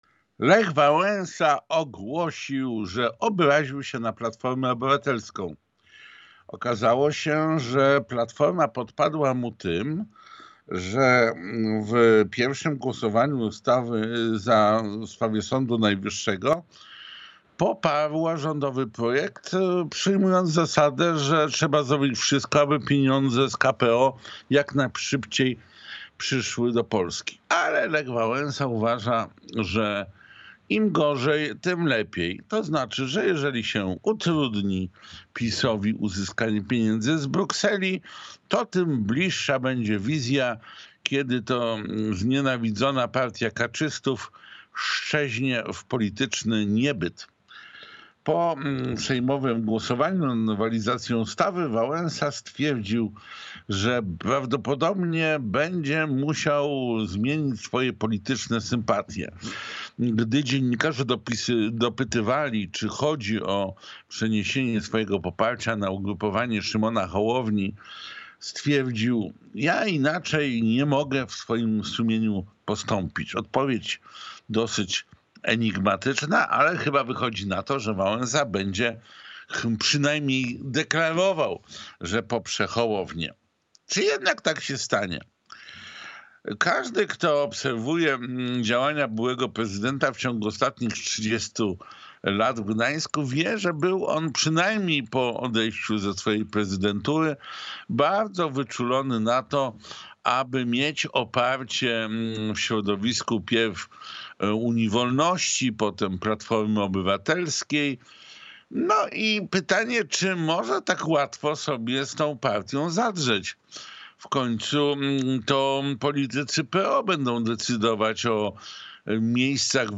Felieton Piotra Semki